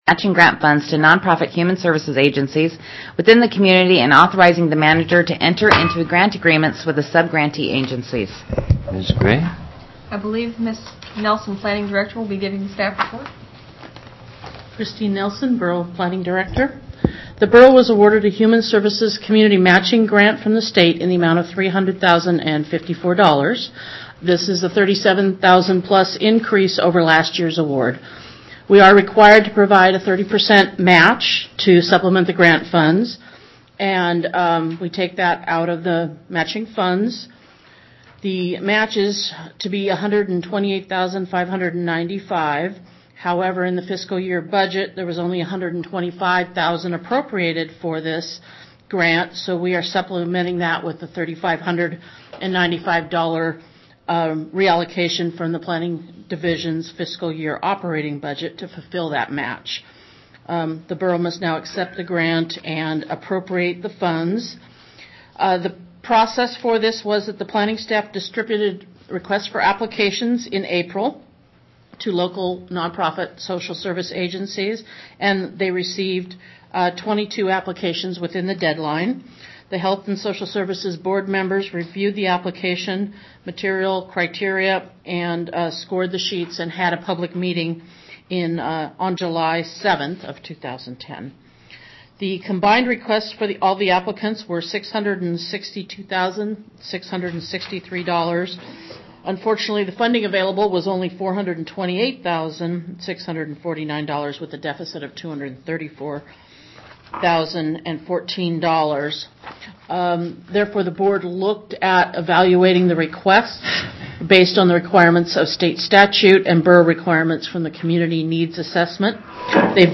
Borough Assembly Meeting, August 17, 2010
Radio Free Palmer broadcasts regular Borough Assembly Meetings as a live stream and an on demand download.